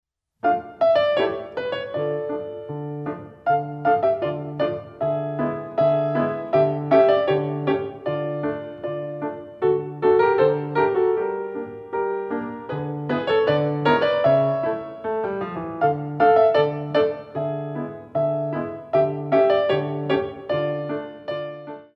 Pas De Bourree Exercise